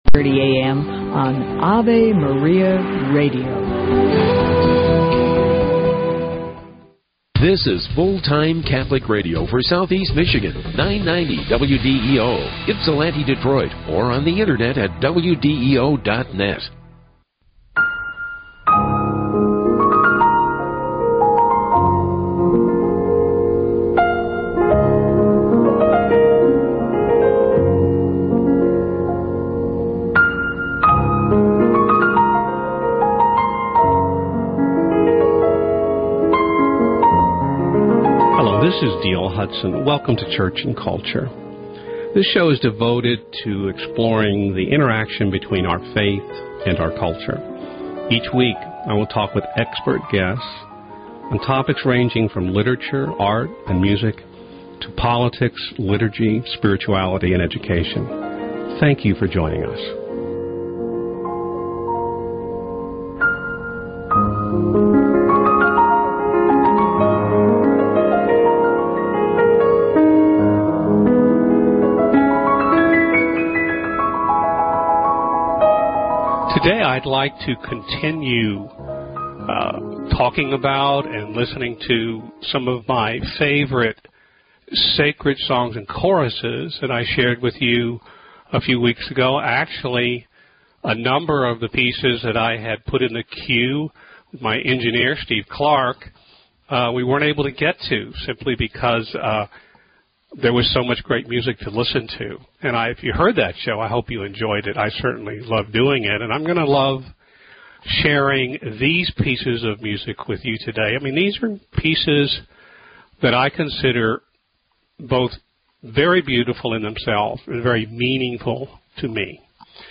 a second program where I share my favorite sacred songs and programs, music that has changed my life, offered healing and inspiration